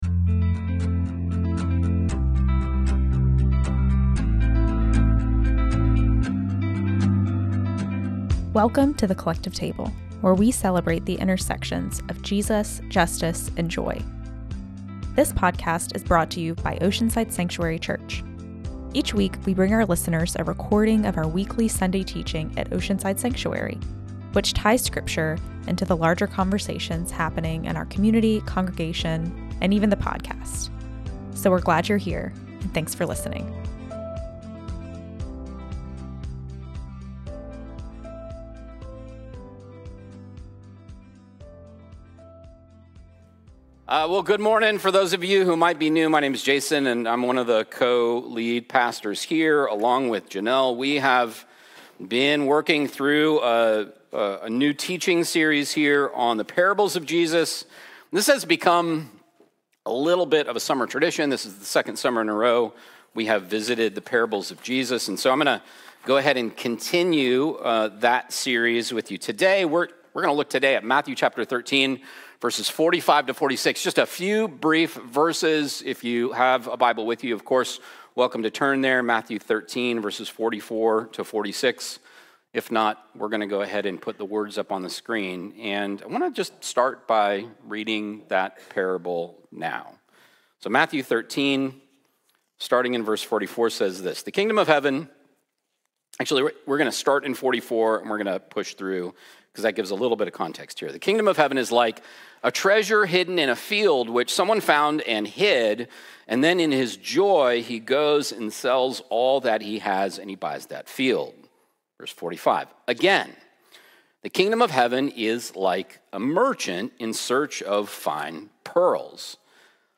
Each week, we bring our listeners a recording of our weekly Sunday teaching at Oceanside Sanctuary, which ties scripture into the larger conversations happening in our community, congregation and podcast.
This teaching was recorded on Sunday, July 27th, 2025 at The Oceanside Sanctuary Church (OSC) in Oceanside, CA.